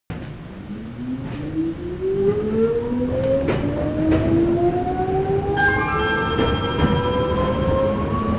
8500系車内チャイム
秩父鉄道へ譲渡された車両に設置されているものと同じです。